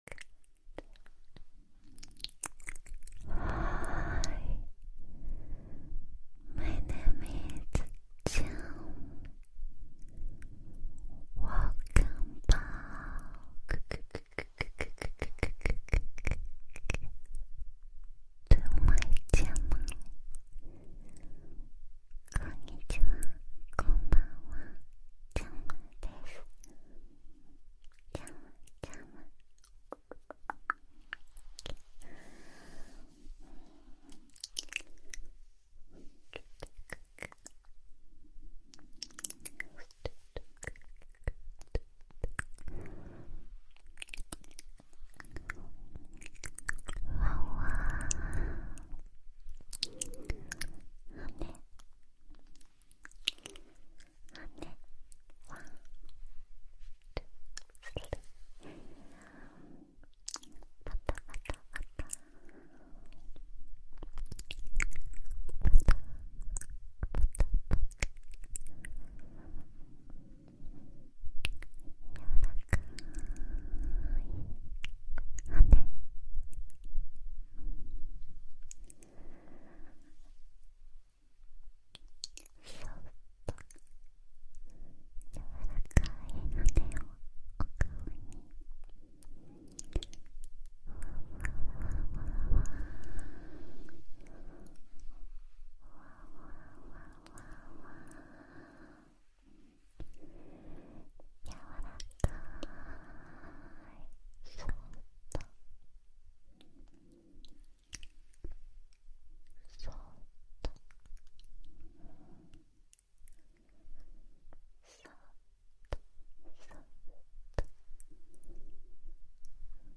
Help me sound effects free download
You Just Search Sound Effects And Download. funny sound effects on tiktok Download Sound Effect Home